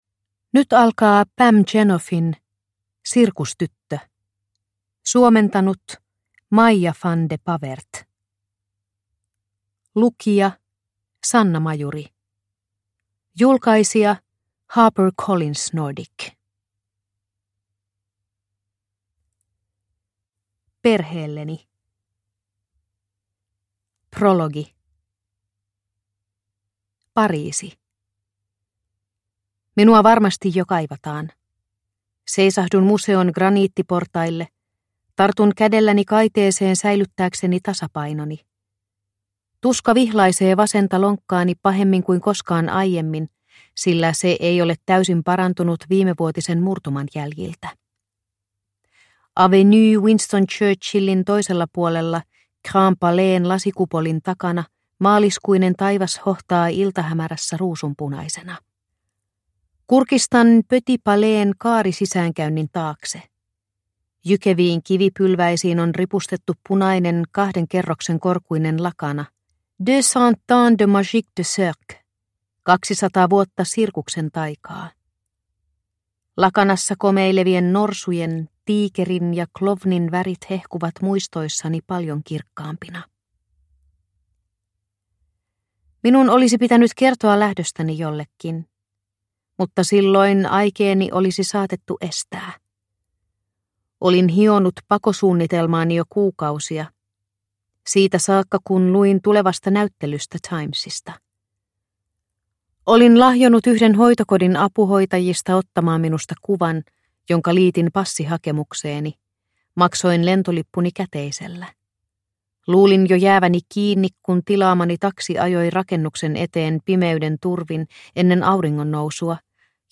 Sirkustyttö – Ljudbok – Laddas ner